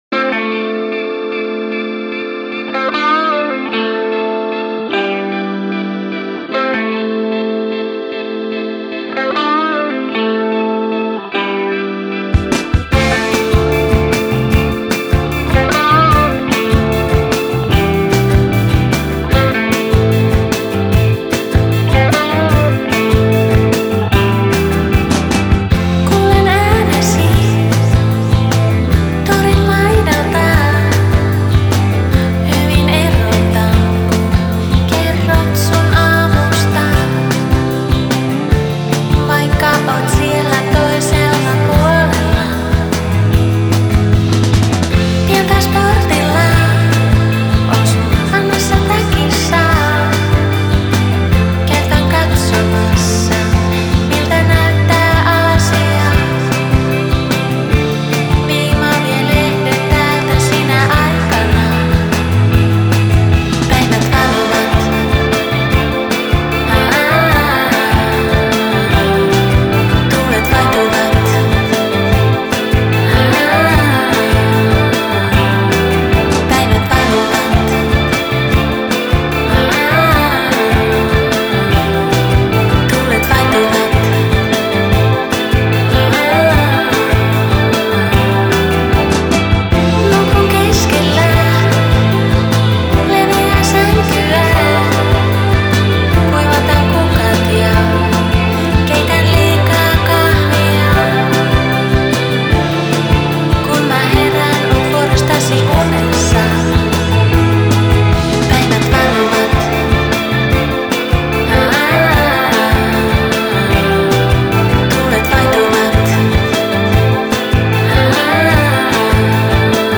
Style: Dream Pop